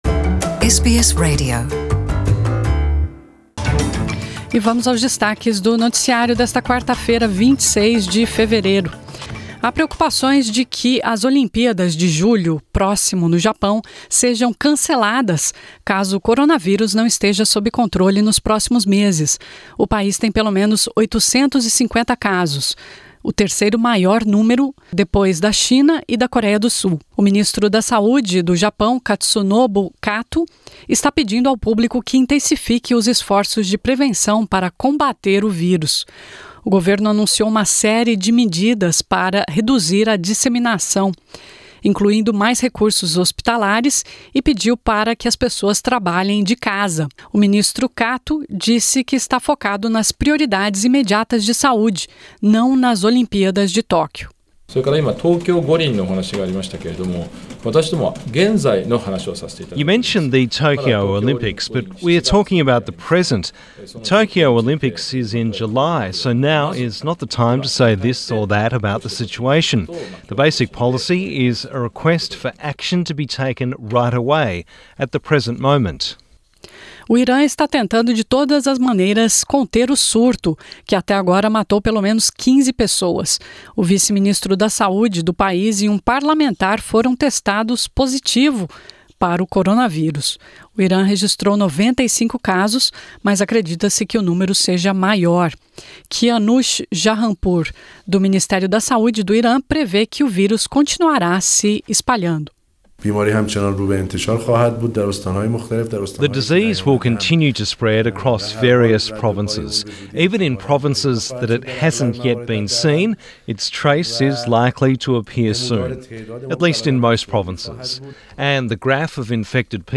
Ouça as notícias mais importantes do dia, em português.